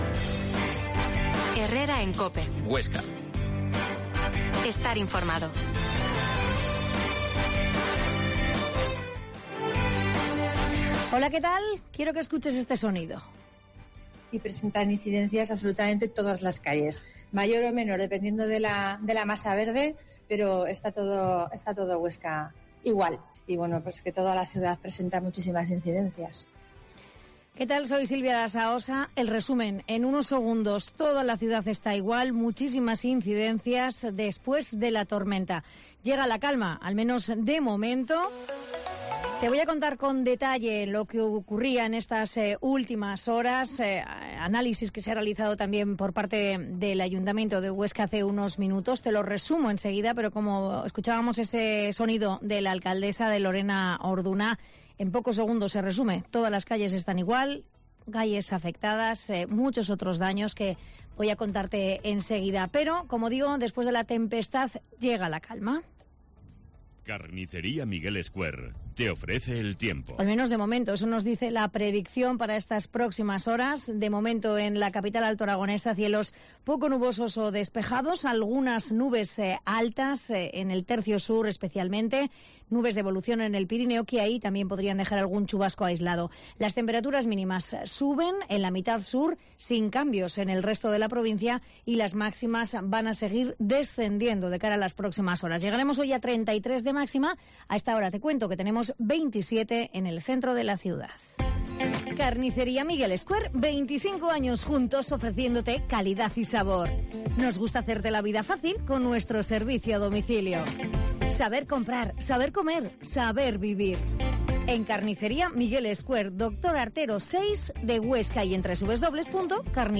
Herrera en COPE Huesca 12.50h Reportaje de los efectos de la tormenta en Huesca